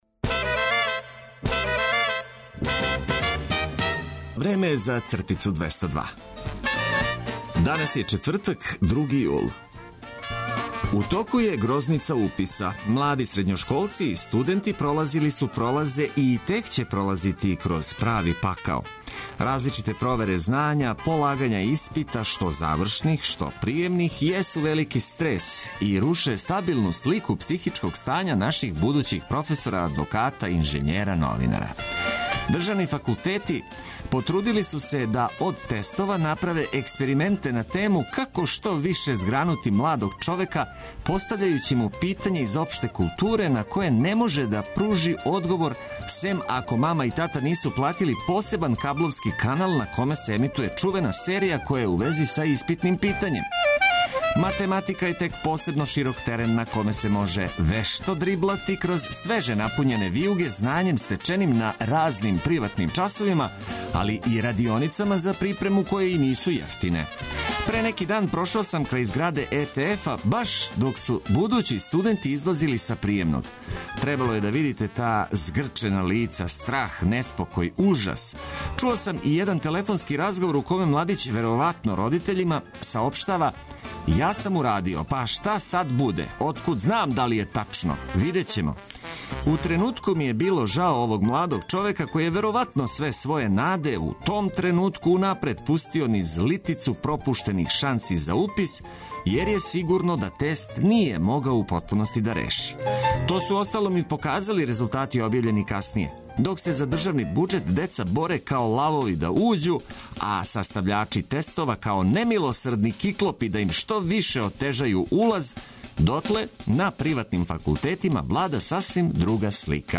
Јутро започињемо уз добру музику, шарамо га корисним информацијама и китимо оптимизмом.